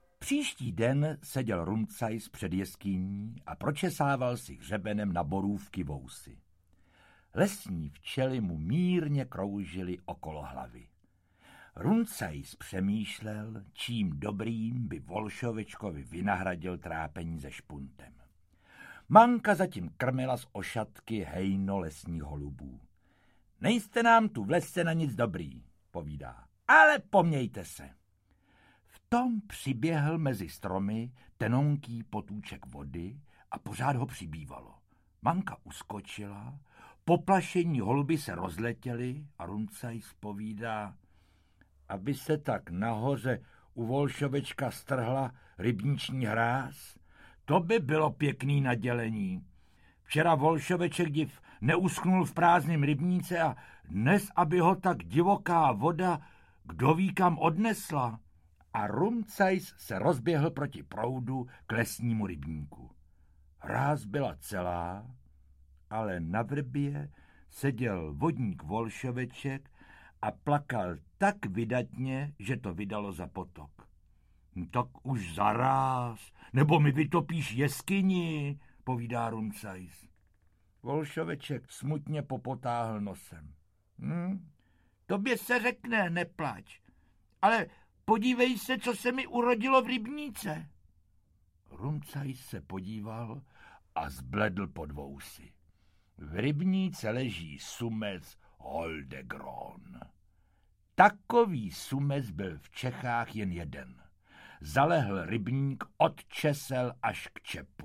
O Rumcajsovi, Cipískovi a vodníku Volšovečkovi audiokniha
Ukázka z knihy
Doprovodná hudba Václava Lídla je původní, připomene tedy posluchači nejen TV večerníčky, ale i jejich zvukovou verzi na kompaktech a kazetách, jejichž osobitému kouzlu a umělecké úrovni nezůstal nový titul nic dlužen.
• InterpretJosef Dvořák